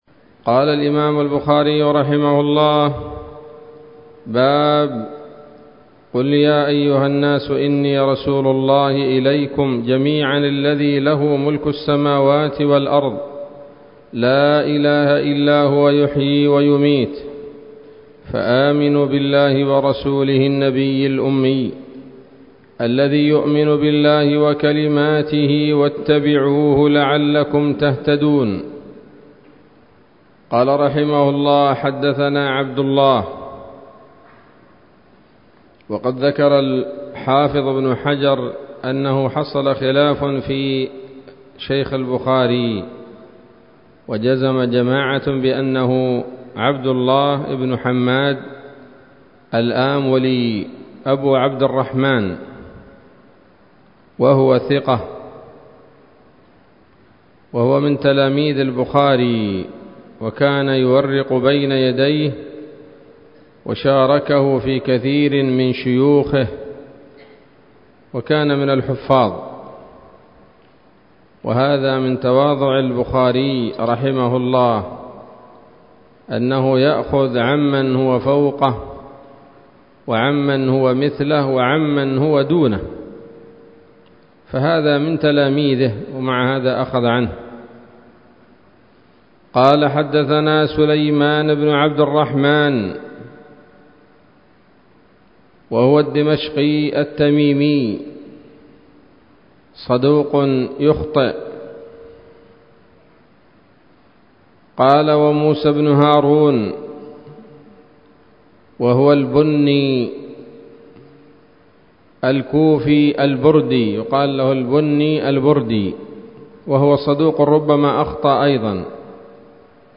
الدرس الثامن بعد المائة من كتاب التفسير من صحيح الإمام البخاري